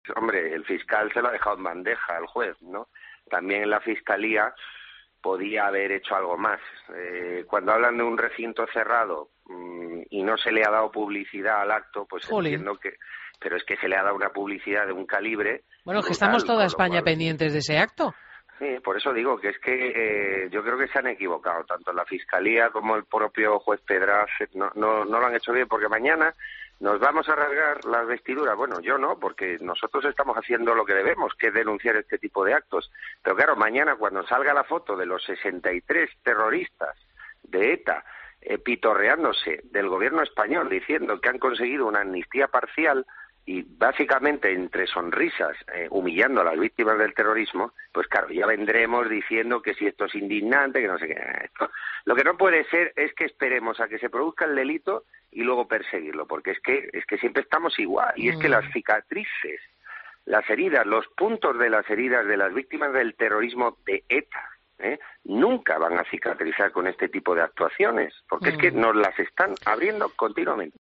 Entrevistas en Fin de Semana